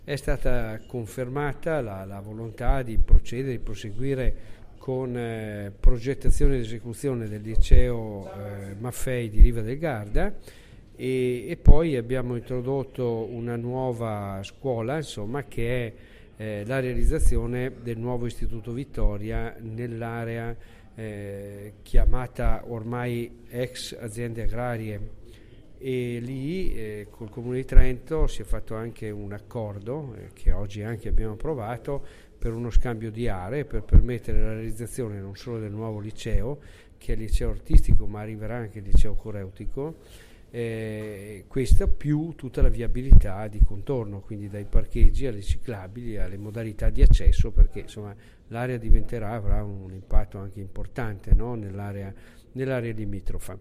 (fm) (fm) Fonte: Ufficio Stampa Istruzione e formazione Pubblica Amministrazione Versione Stampabile Immagini Visualizza Audio Intervista all'assessore Gilmozzi Scarica il file (File audio/mpeg 838,77 kB)